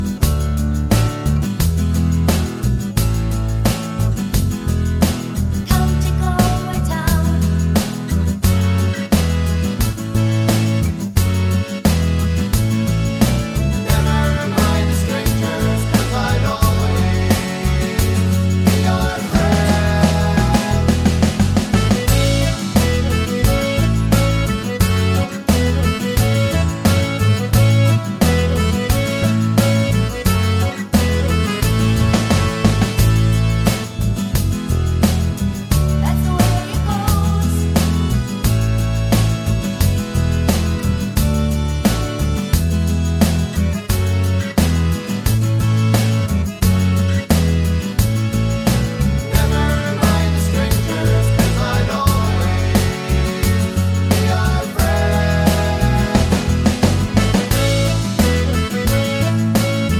no Backing Vocals Irish 3:41 Buy £1.50